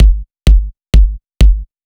VTDS2 Song Kit 04 Pitched Sneaking On The DF Kick.wav